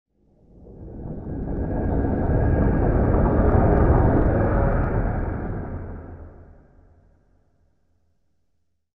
creepy-sound